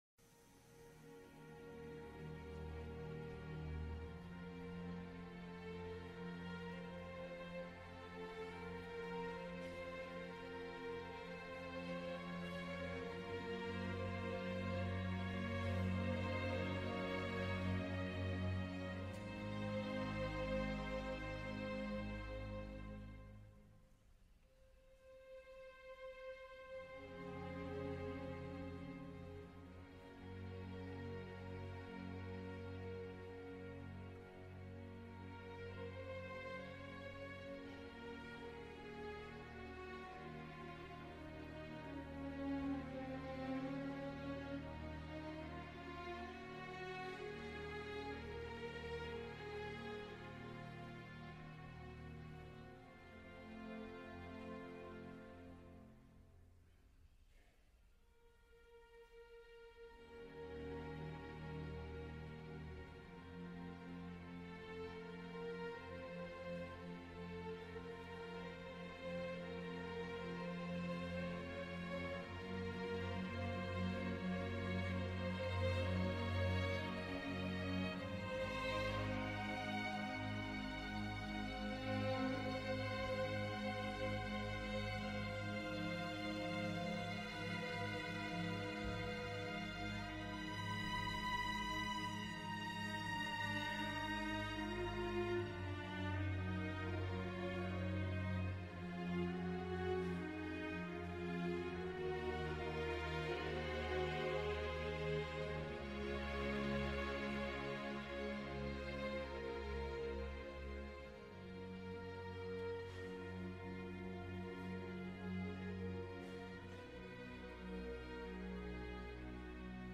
Leonard Slatkin Conducts The Detroit Symphony Orchestra In 2016
At Fisher Music Centre